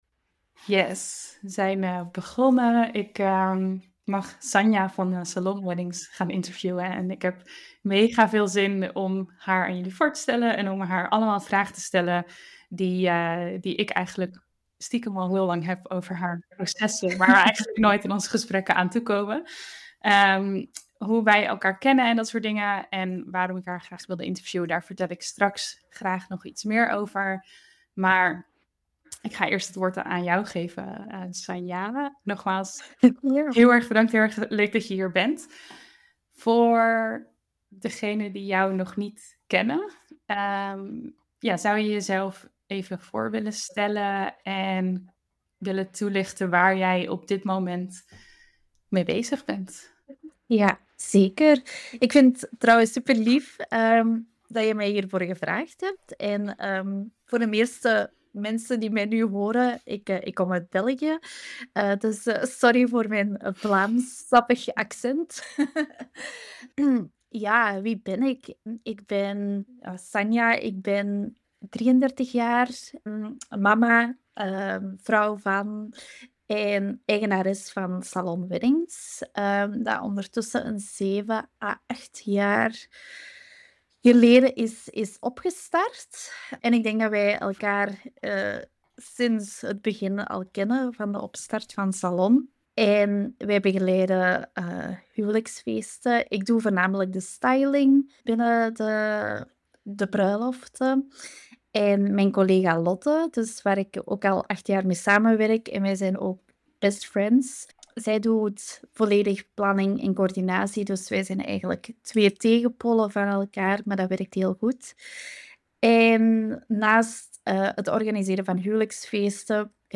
In dit interview